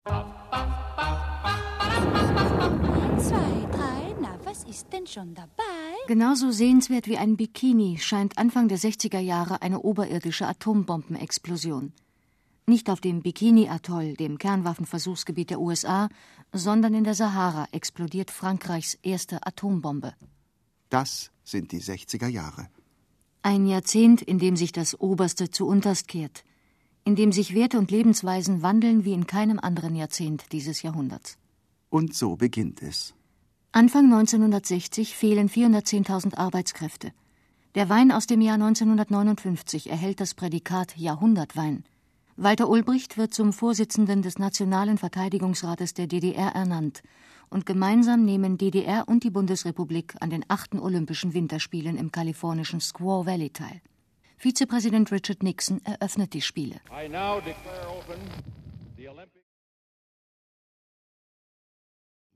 ausdrucksstark,charaktervoll, auch dunkles Timbre, sprachrhythmisch gewandt, warmer Erzählton, klarer journalistischer Ton, klangvoll, geschmeidig
Sprechprobe: Industrie (Muttersprache):
female german speaker with warm and colourful voice